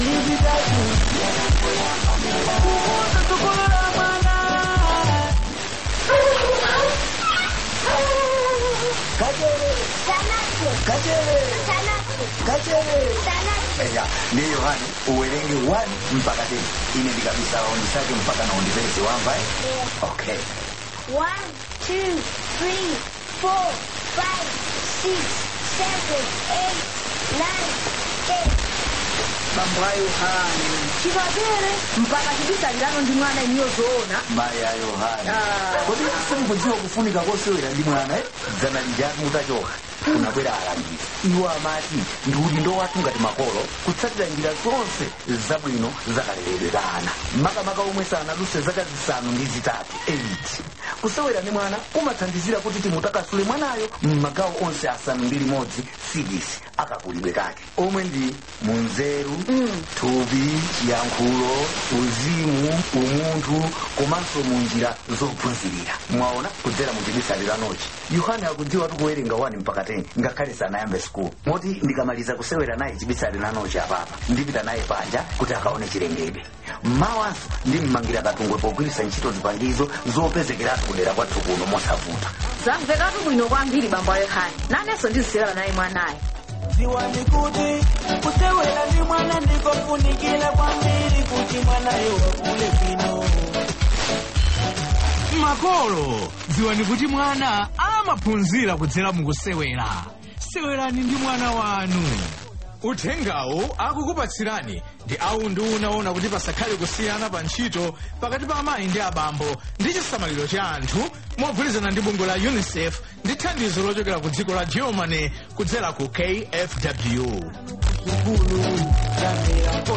Advert: Play With Your Children For Development